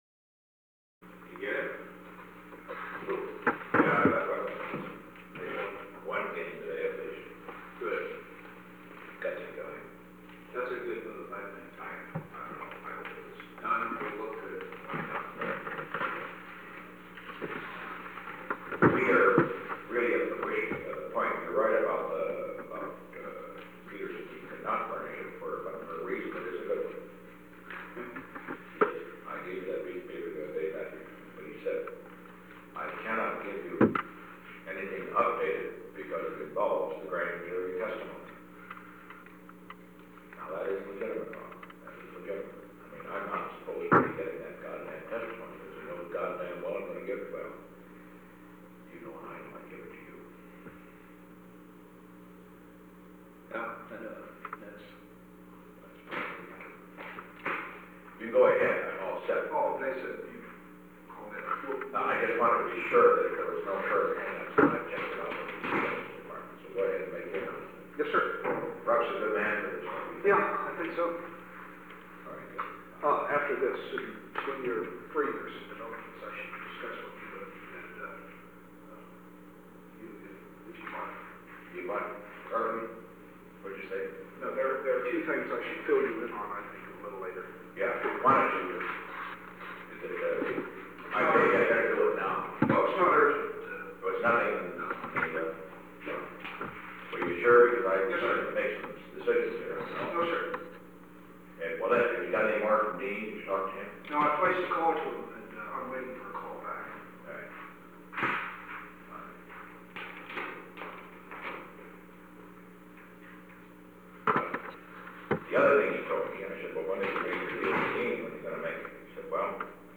Conversation No. 906-12 Date: April 27, 1973 Time: 4:41 pm - 5:00 pm Location: Oval Office The President met with H. R. (“Bob”) Haldeman.
Secret White House Tapes